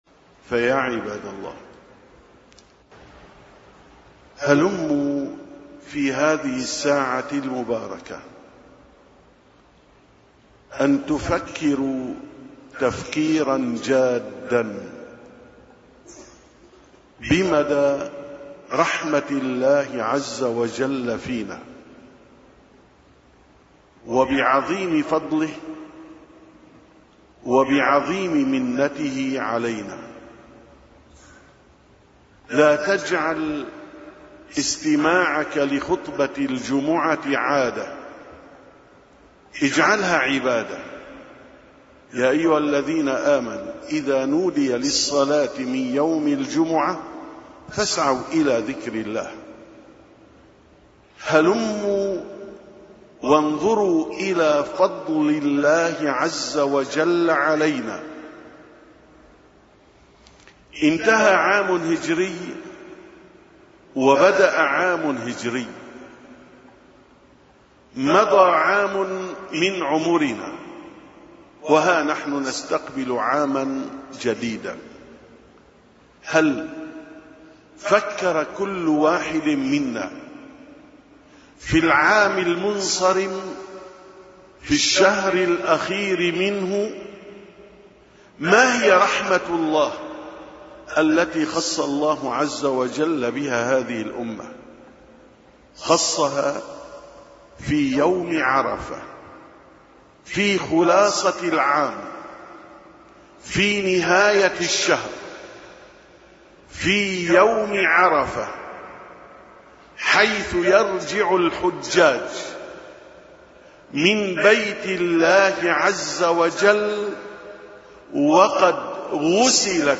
878ـ خطبة الجمعة: اجعلوا هذا العام الهجري مميزًا